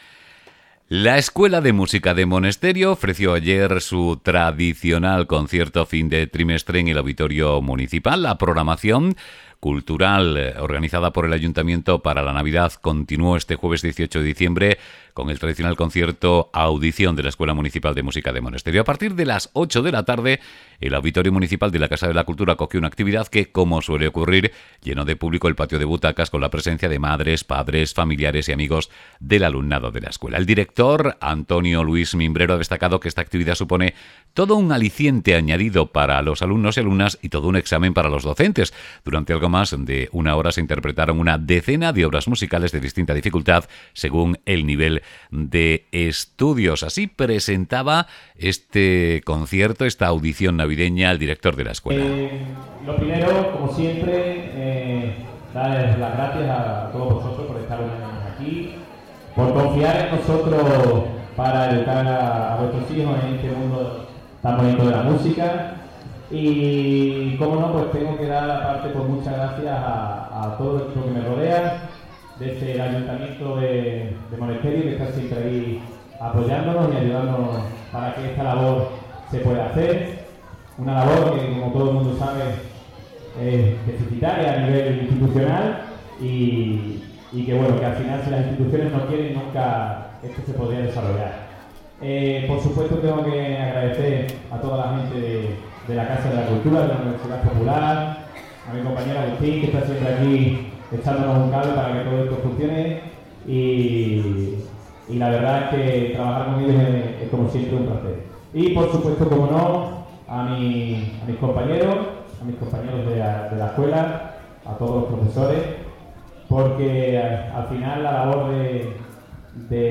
La Escuela de Música de Monesterio ofrece una audición fin de trimestre en el auditorio Municipal de Monesterio
bIKlVAUDICINESCUELAMUSICA.mp3